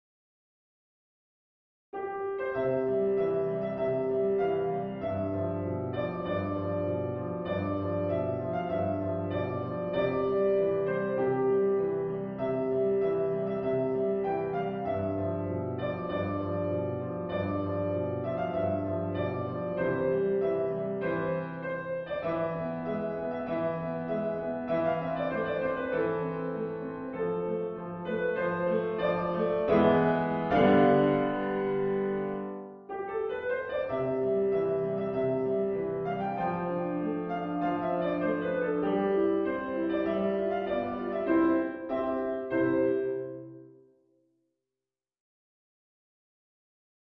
リピートは基本的に省略していますが、D.C.を含むものは途中のリピートも全て再現しています